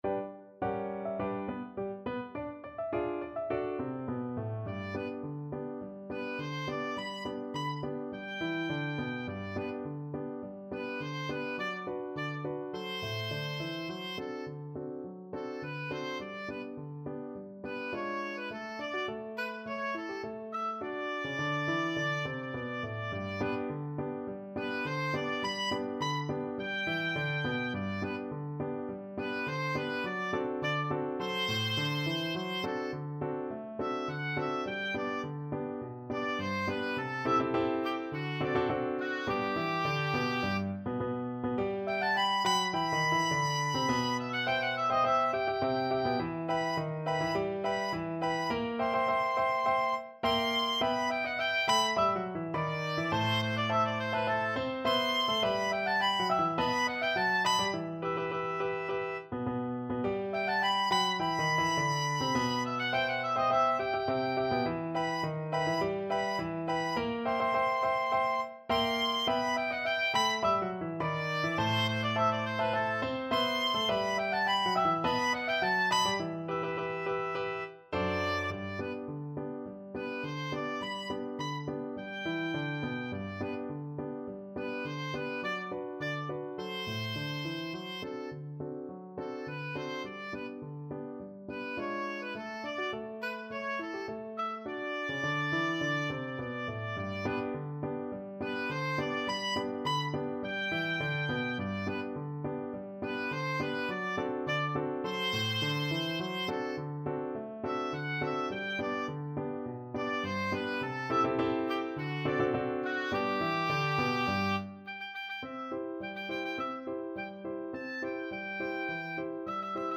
Oboe
G major (Sounding Pitch) (View more G major Music for Oboe )
Quick March = c.104
Classical (View more Classical Oboe Music)